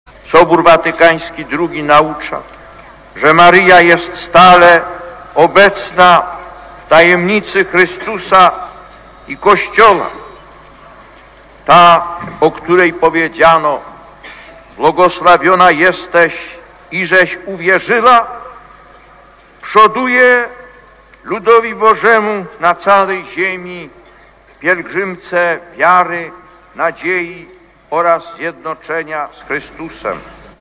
Głos Papieża: (